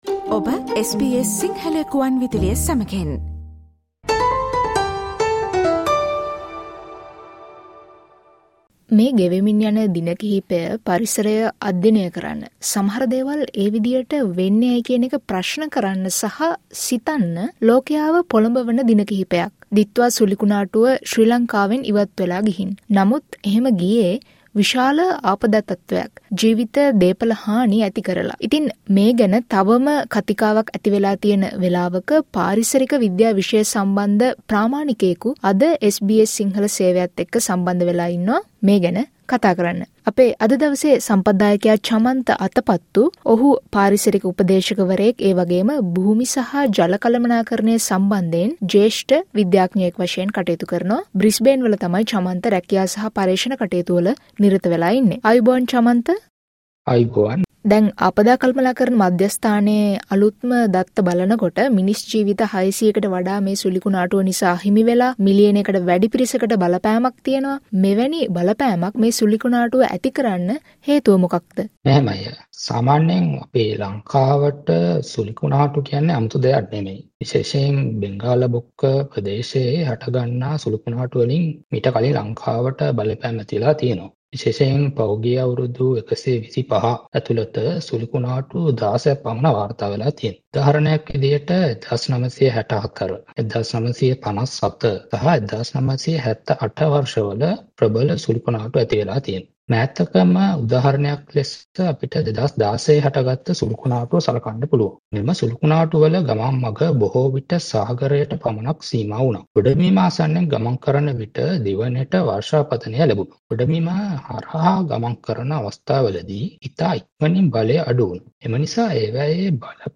මෙවැනි දැඩි බලපෑමක් ඇති වීමට හේතුව, දේශගුණික විපර්යාස, මිනිස් ක්‍රියාකාරකම්වල සම්බන්ධය සහ ගංවතුර පාලනය පිළිබඳව SBS සිංහල සේවය ගෙන එන සාකච්ඡාවට සවන් දෙන්න.